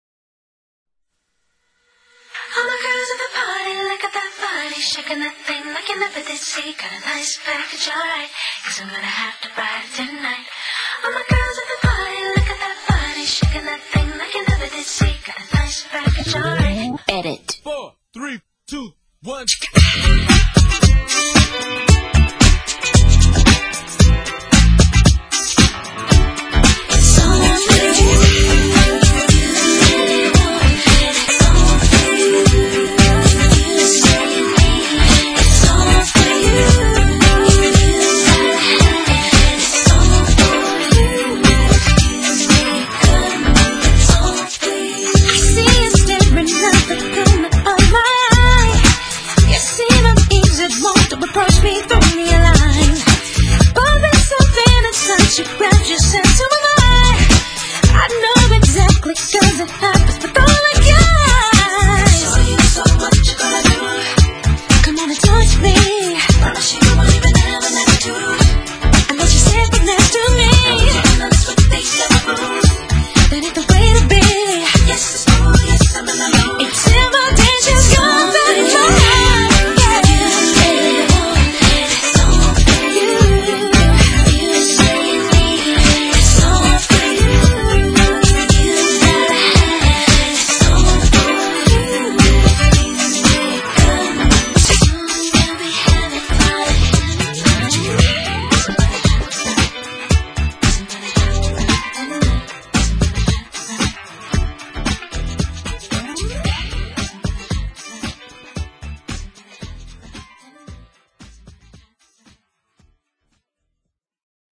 BPM113--1
Audio QualityPerfect (High Quality)